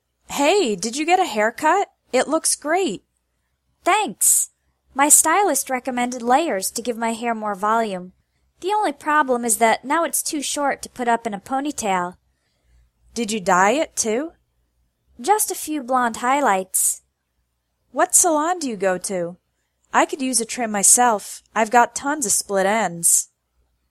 English Conversation: Did you get a haircut?